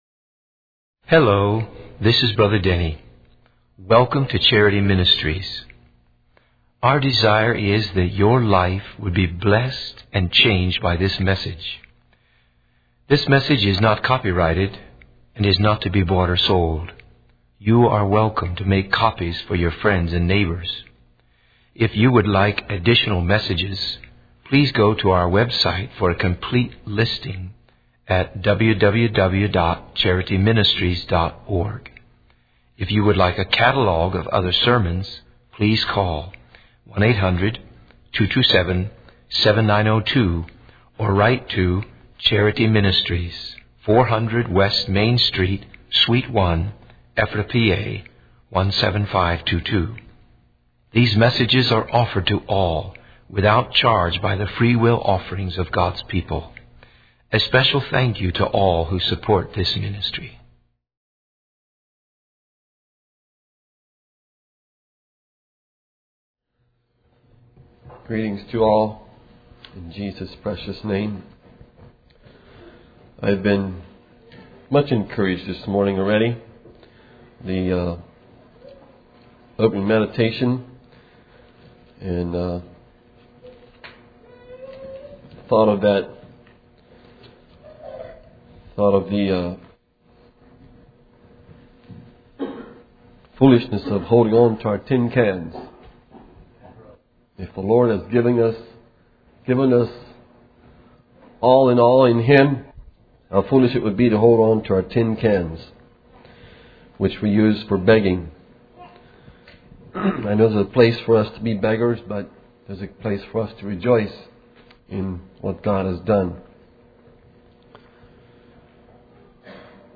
In this sermon, the preacher discusses four areas where Christians may fail in their spiritual journey. The first area is the failure to fully embrace sanctification and healing in body, soul, and spirit. The second area is the failure to fit into a local body of believers, often due to a strong sense of individuality.